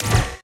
SCIMisc_Sci Fi Shotgun Reload_05_SFRMS_SCIWPNS.wav